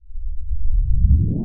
TIME_WARP_Start_01_mono.wav